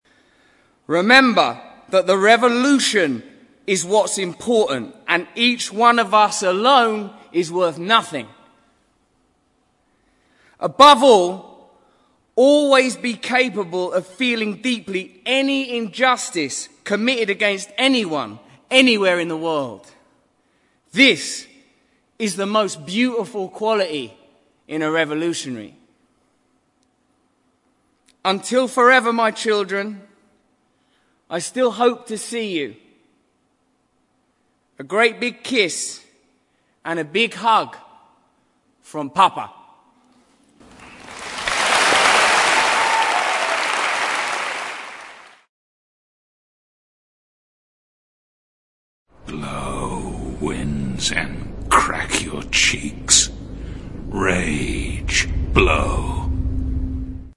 在线英语听力室见信如晤Letters Live 第10期:'罗素.布兰德'读信:长大成为优秀革命家(2)的听力文件下载,《见信如唔 Letters Live》是英国一档书信朗读节目，旨在向向书信艺术致敬，邀请音乐、影视、文艺界的名人，如卷福、抖森等，现场朗读近一个世纪以来令人难忘的书信。